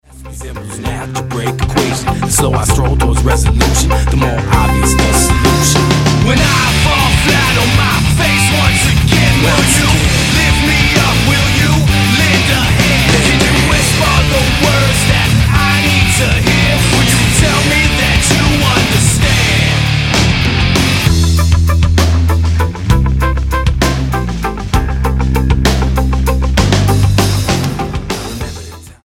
STYLE: Hard Music
Here is a good rap rock album